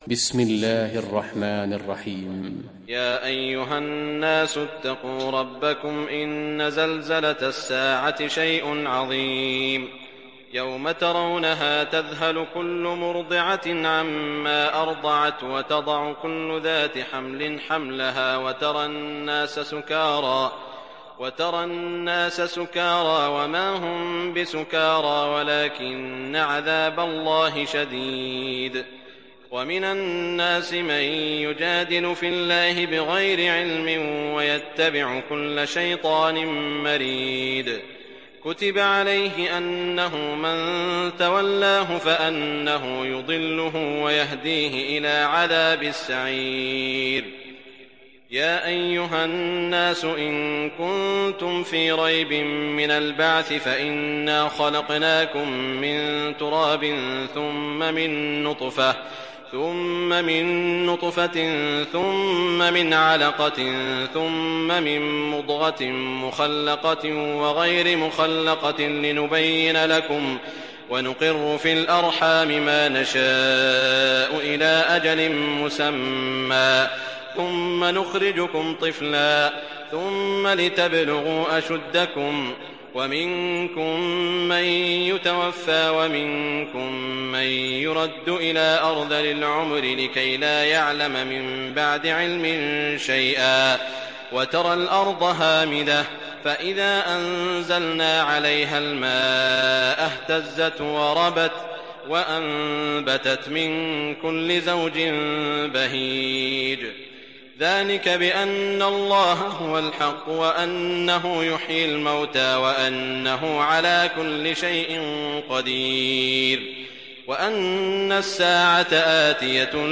تحميل سورة الحج mp3 بصوت سعود الشريم برواية حفص عن عاصم, تحميل استماع القرآن الكريم على الجوال mp3 كاملا بروابط مباشرة وسريعة